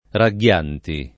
Ragghianti [ ra ggL# nti ] cogn.